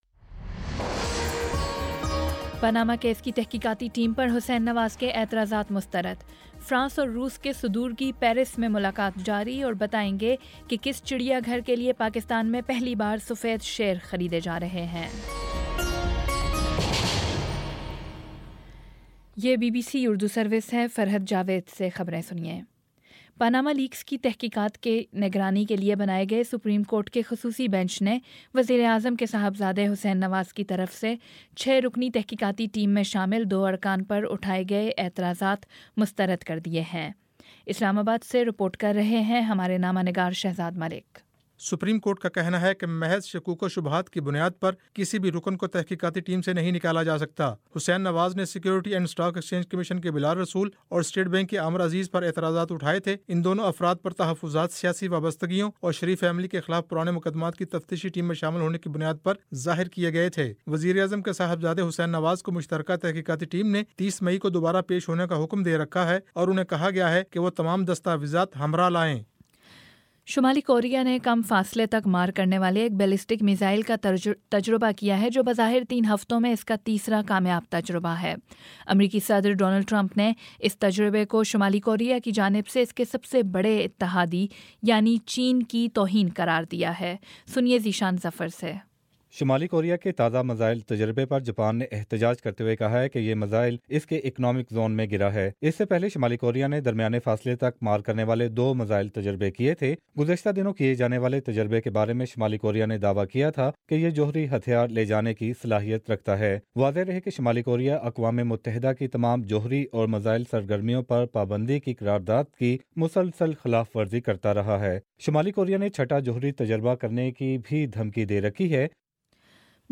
مئی 29 : شام سات بجے کا نیوز بُلیٹن